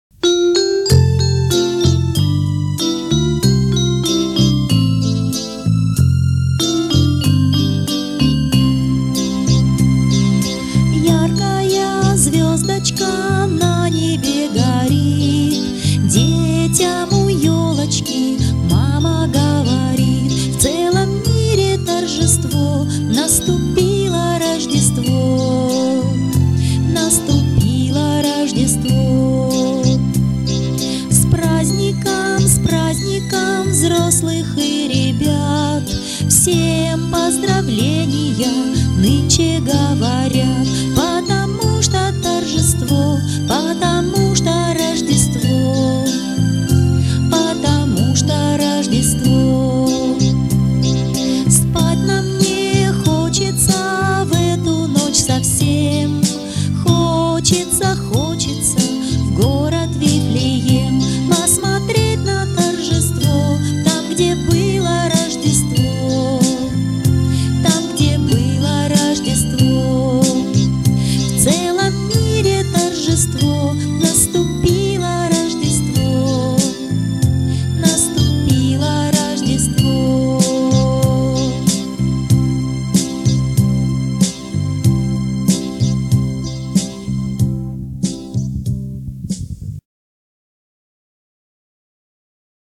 Звучит фонограмма песни